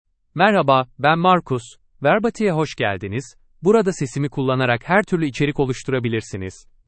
MarcusMale Turkish AI voice
Marcus is a male AI voice for Turkish (Turkey).
Voice sample
Male
Marcus delivers clear pronunciation with authentic Turkey Turkish intonation, making your content sound professionally produced.